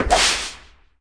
Block Lava Sound Effect
block-lava-1.mp3